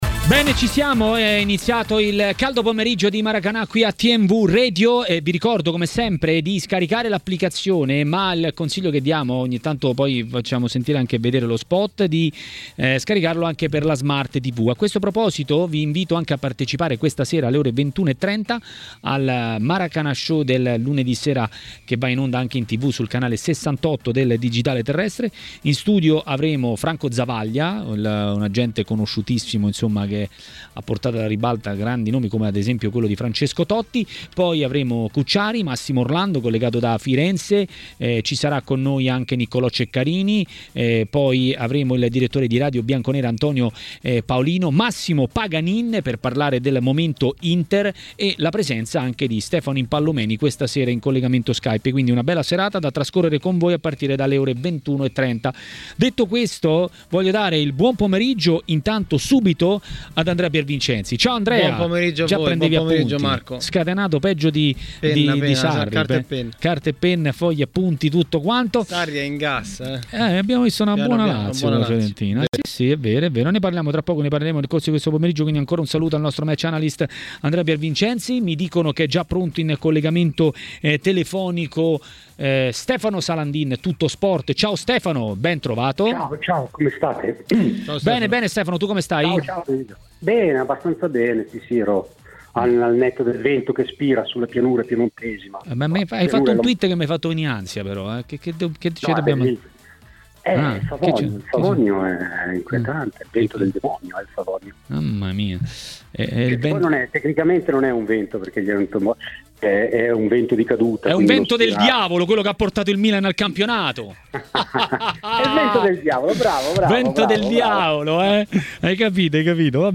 ha commentato la giornata di campionato a Maracanà, trasmissione di TMW Radio.